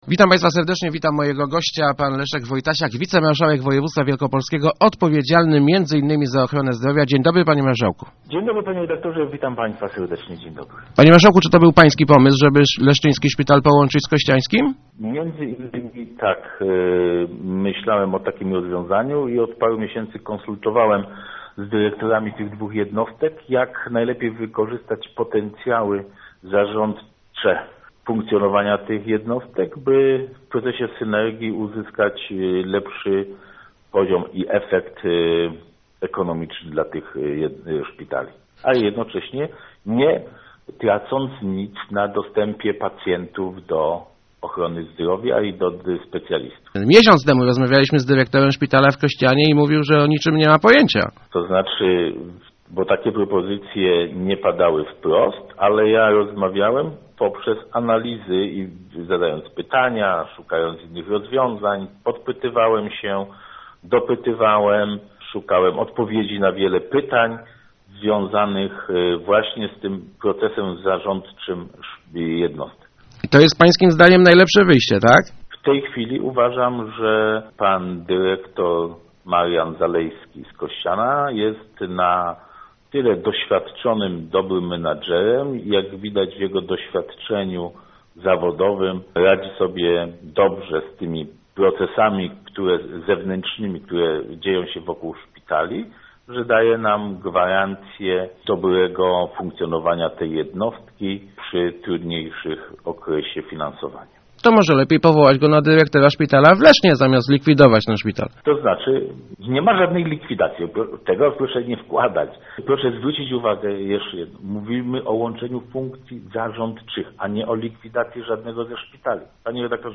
Szkoda, �e pracownicy szpitala zamiast prostestowa�, nie porozmawiali z nami - mówi� w Rozmowach Elki Leszek Wojtasiak, wicemarsza�ek Województwa Wielkopolskiego odpowiedzialny za s�u�b� zdrowia. Zapewni� on, �e po��czenie leszczy�skiego szpitala z ko�cia�skim nie ograniczy �adnych funkcji placówki.